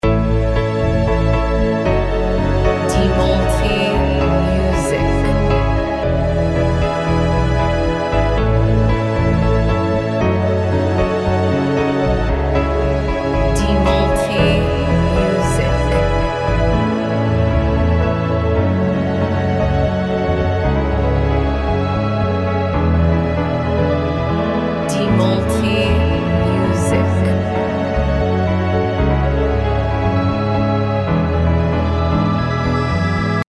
Modern Piano Instrumental